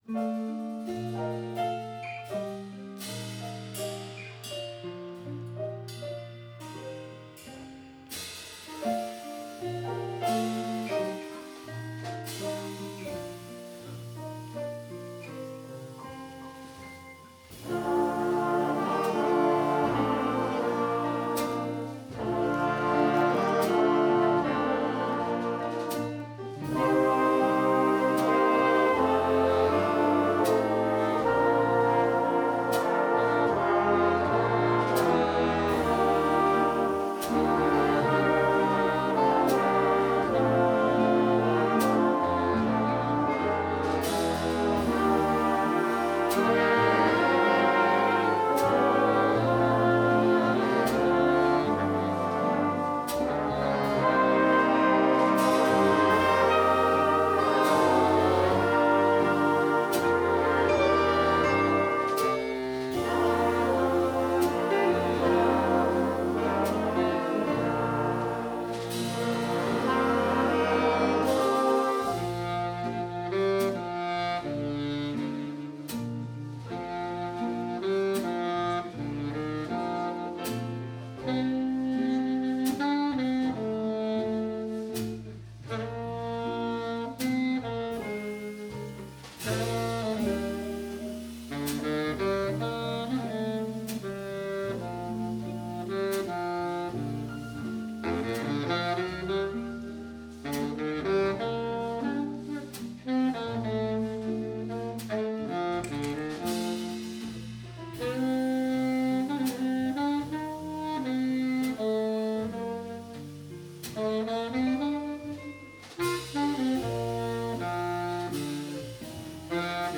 Vi garanterer, at I kommer i julestemning når I hører de jazzsvingende juleklassikere, flere af dem med vokal-indslag.
Udstyret er én digital stereo mikrofon, ikke en studieoptagelse !